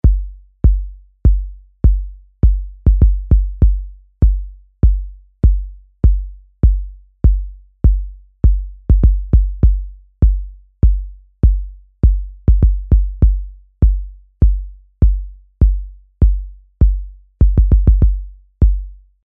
Per default ist die TR808 geladen.
Dreht man die Effekte alle zurück, so beginnt man mit diesem noch recht unspektakulären Klang: